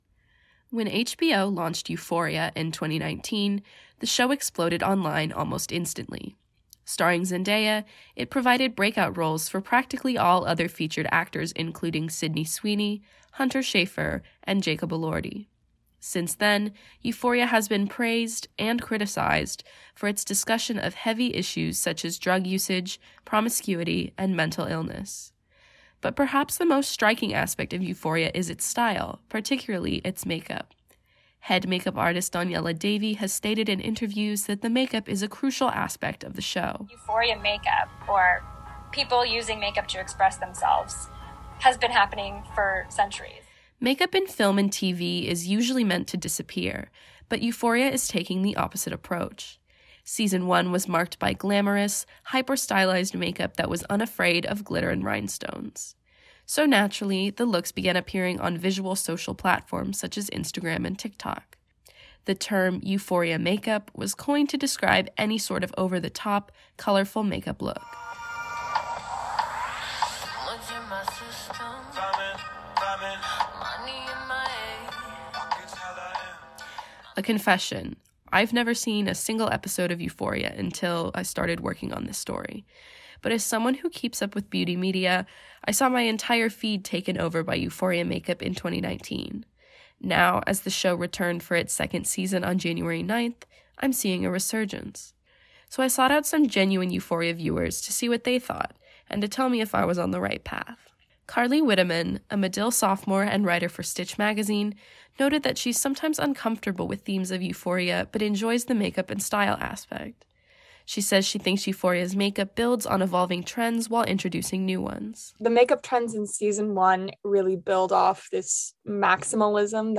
WNUR News